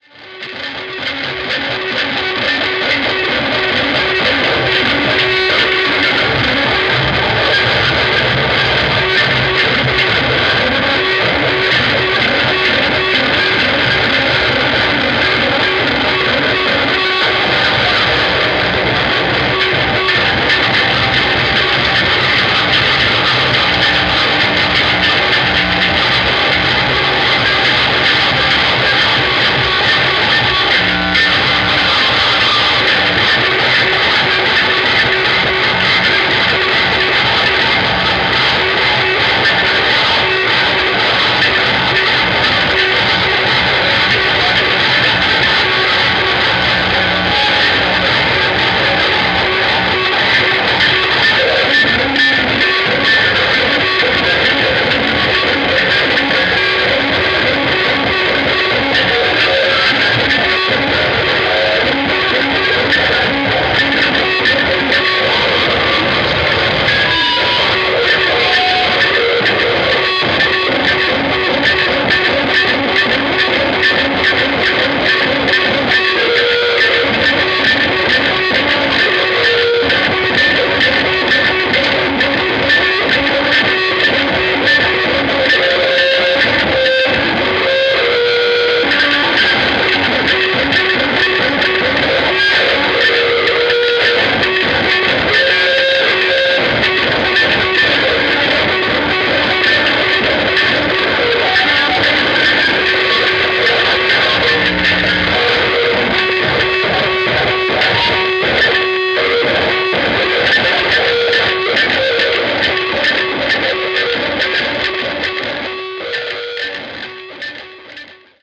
The venue was well past its capacity.